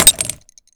grenade_hit_04.WAV